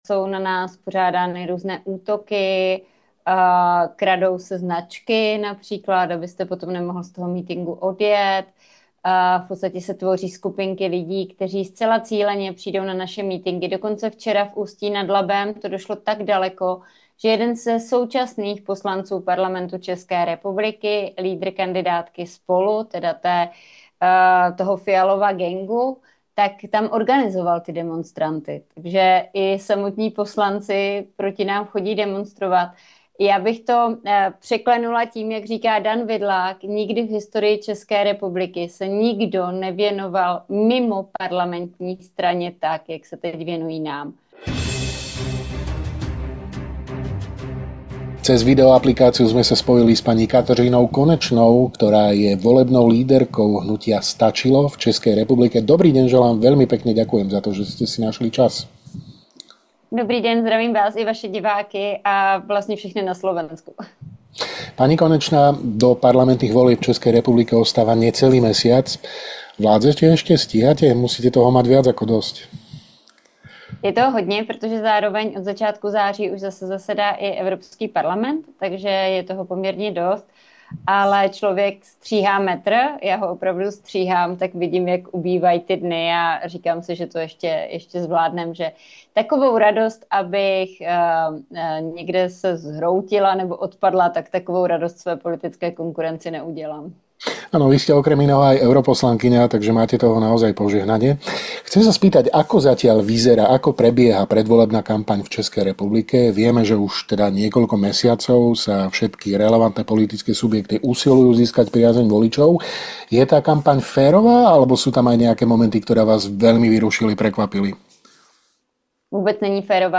Vo videorozhovore pre Hlavné správy otvorene hovorí o – podľa nej – najhoršej predvolebnej kampani, akú za vyše dve dekády v politike zažila. Kritizuje prieskumy verejnej mienky za systematické podhodnocovanie menších strán a upozorňuje aj na pokračujúcu pomoc Ukrajine aktuálnou vládou ČR na úkor domácich občanov.